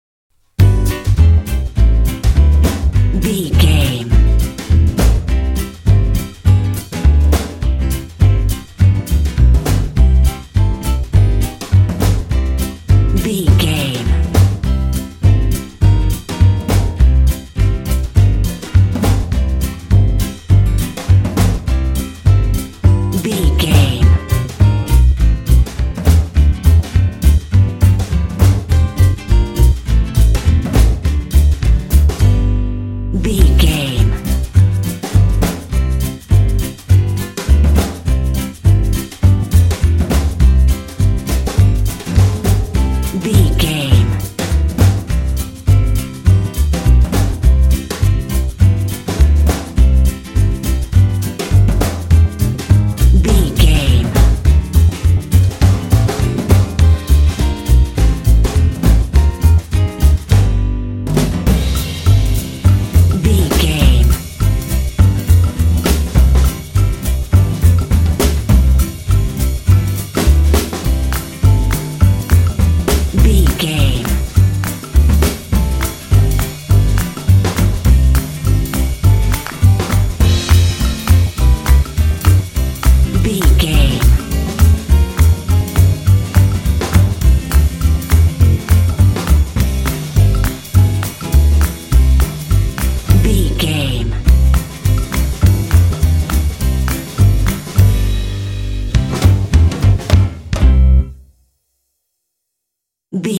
Ionian/Major
playful
uplifting
cheerful/happy
drums
bass guitar
acoustic guitar